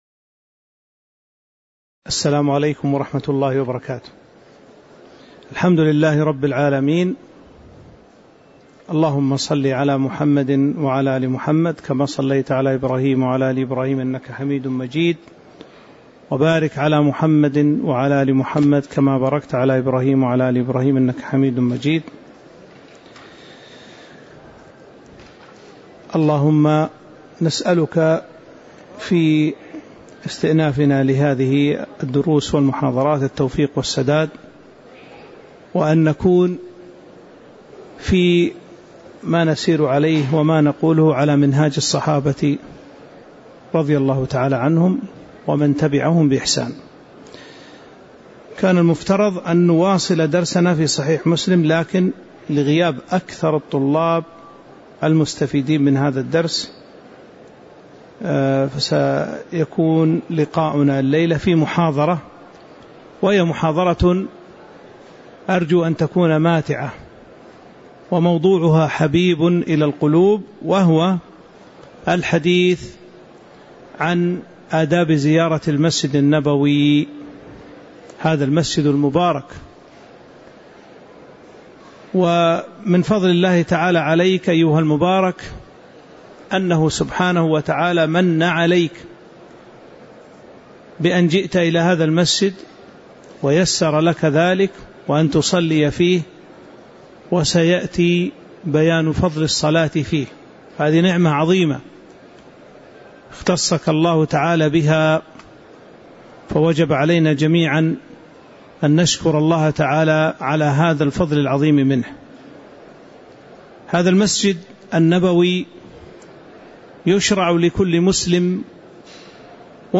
تاريخ النشر ١٤ جمادى الآخرة ١٤٤٦ هـ المكان: المسجد النبوي الشيخ